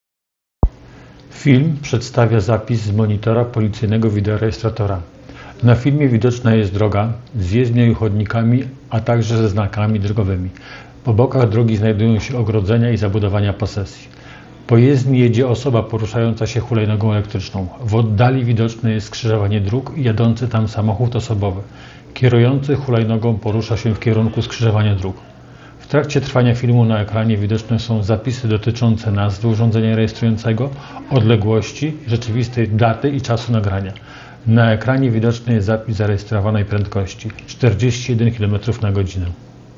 Opis nagrania: Audiodeskrypcja do filmu 18-latek na elektrycznej hulajnodze